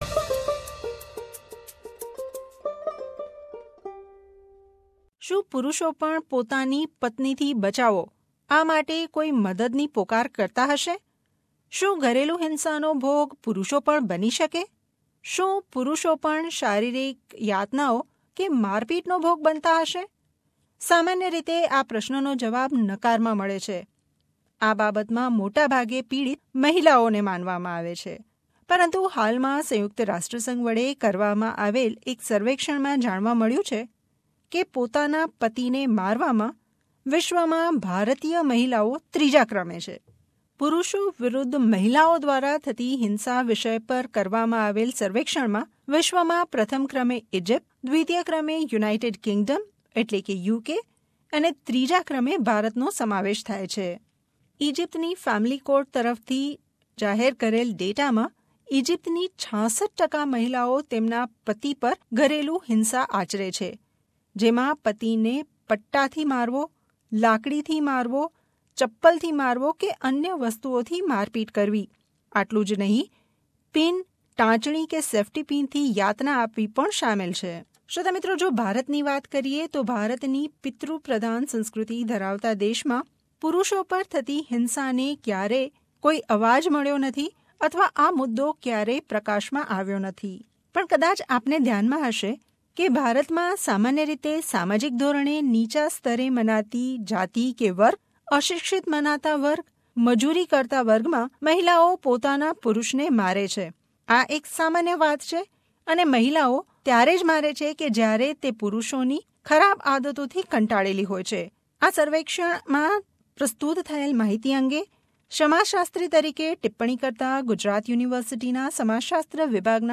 A recent study conducted by the UN has revealed some shocking results regarding the statistics about domestic violence against husbands. India has been ranked third when it comes to abusing and beating husbands. Here are the comments from prominent personalities who are working in related fields.